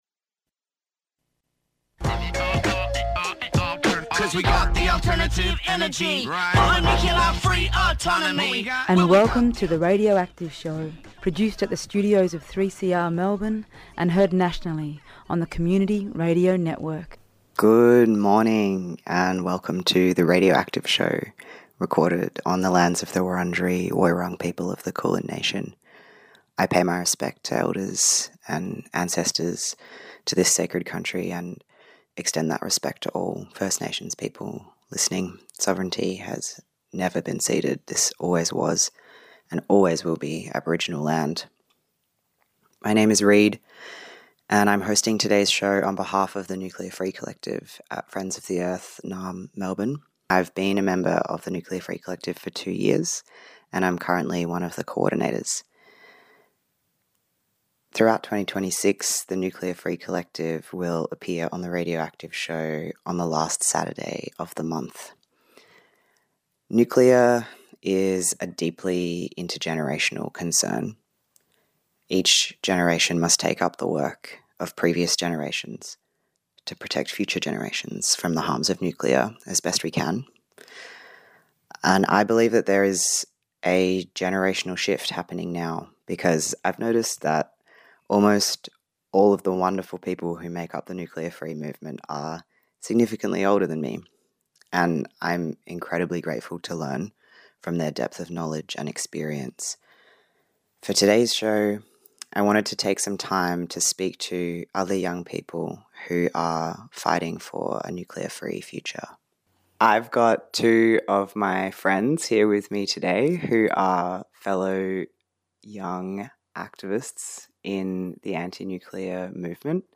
Tweet Radioactive Show Saturday 10:00am to 10:30am Current news and information on nuclear, peace and energy issues.